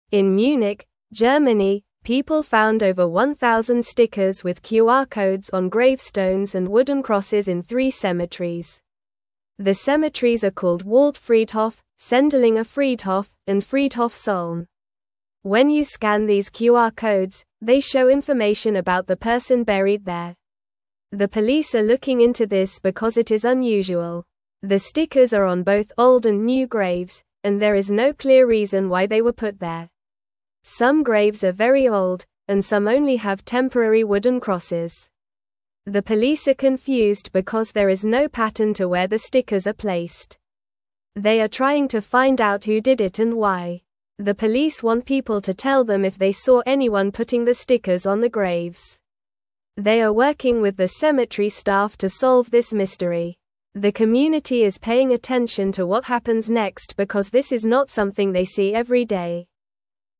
Normal
English-Newsroom-Lower-Intermediate-NORMAL-Reading-Stickers-on-Graves-Tell-Stories-of-the-Past.mp3